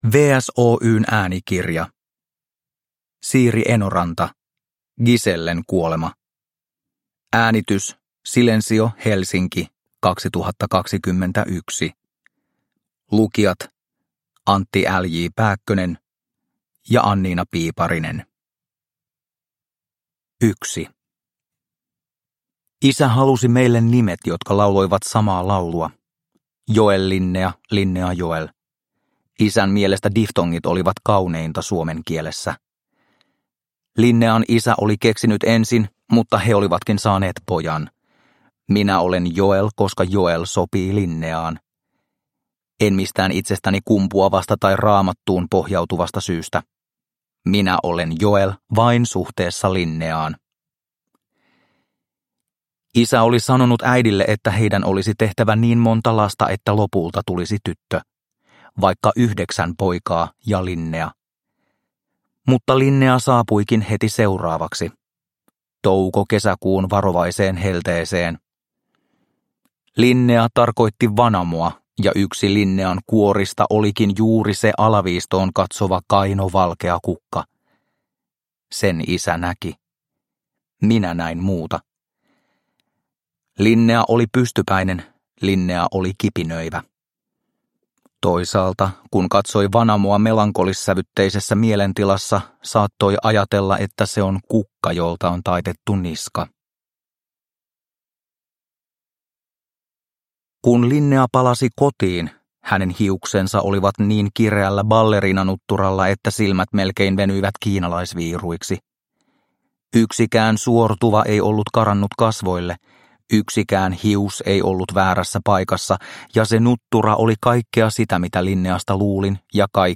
Gisellen kuolema – Ljudbok – Laddas ner